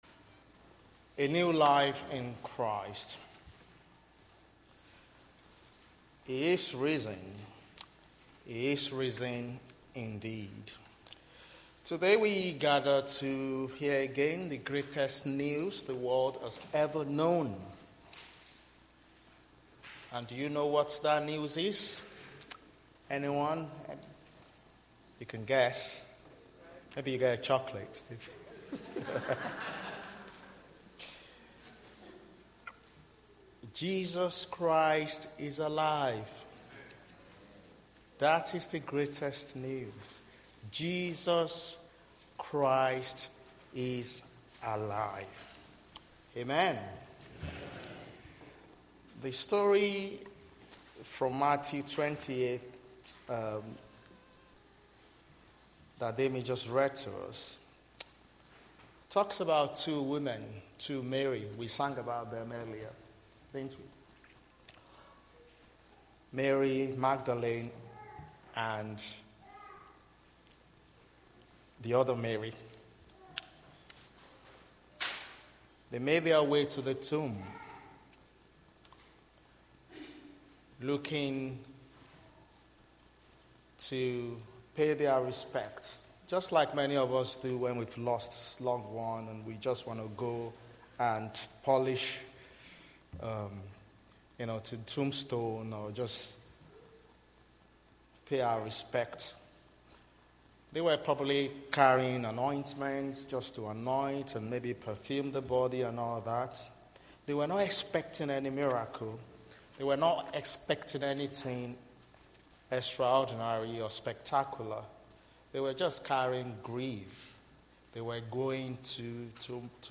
Easter Address: A new life in Christ
Service Type: Communion Service